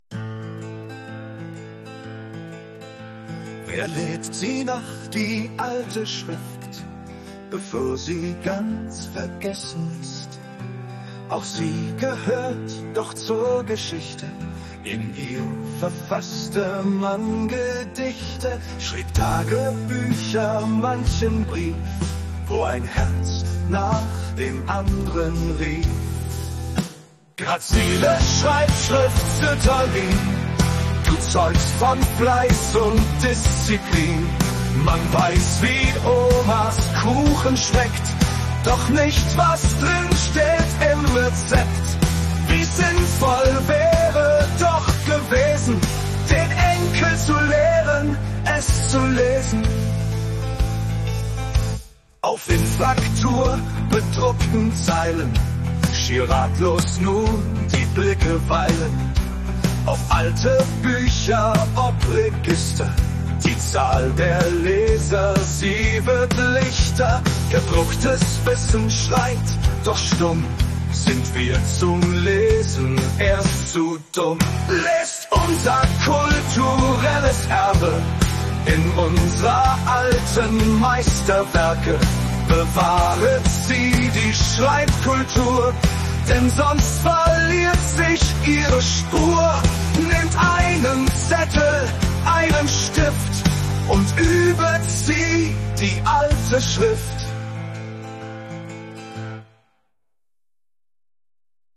Vertonung mittels KI von suno